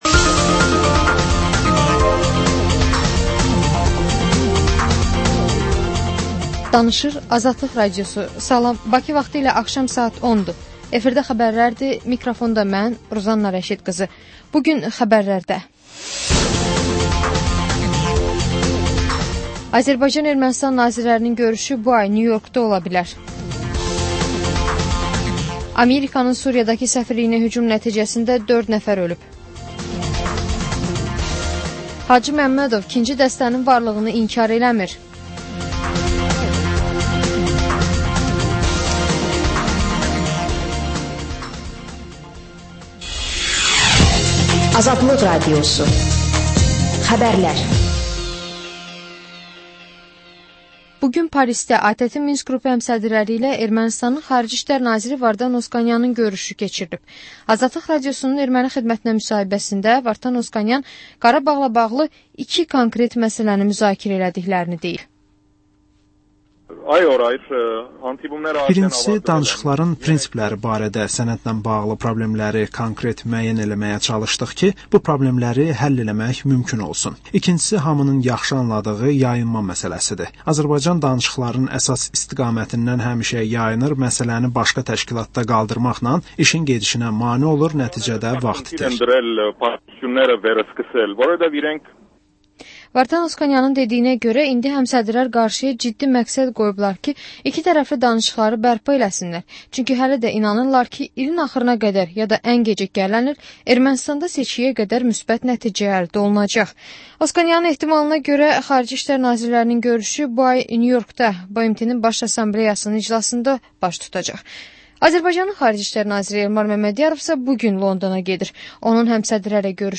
Xəbərlər, reportajlar, müsahibələr. Və: Şəffaflıq: Korrupsiya barədə xüsusi veriliş.